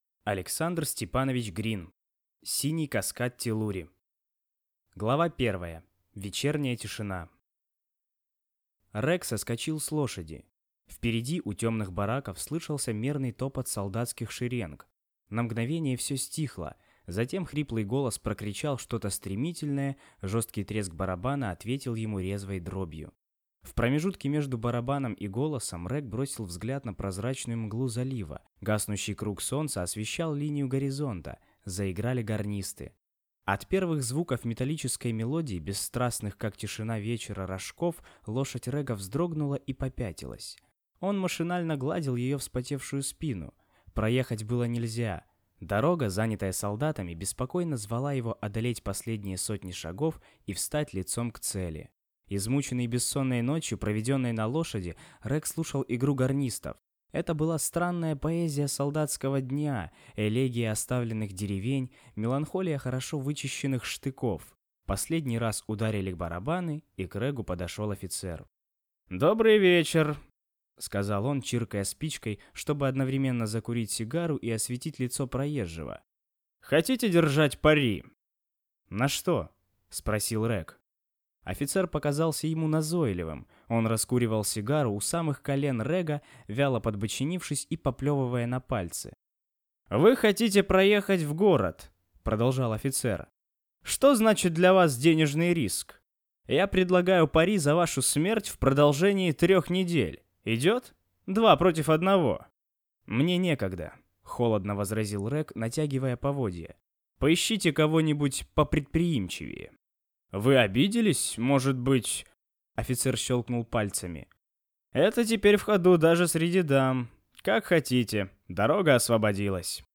Аудиокнига Синий каскад Теллури | Библиотека аудиокниг